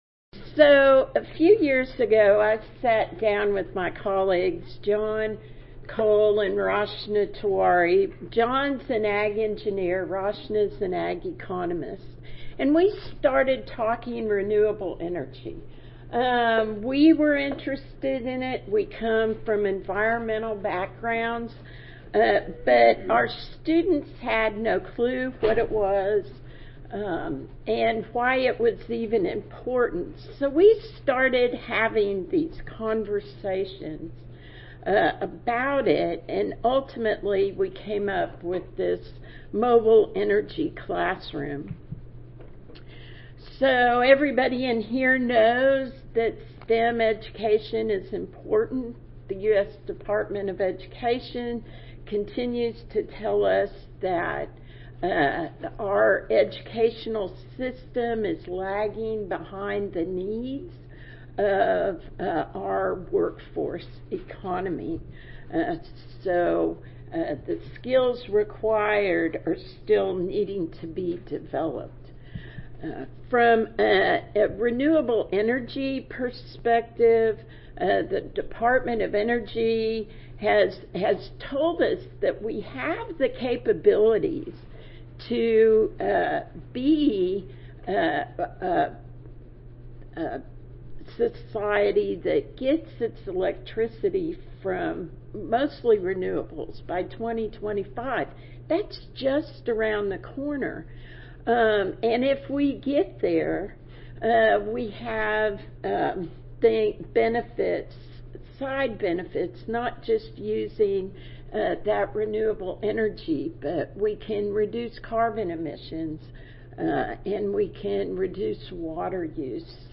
University of Tennessee at Mar Audio File Recorded Presentation